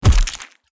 body_hit_finisher_27.wav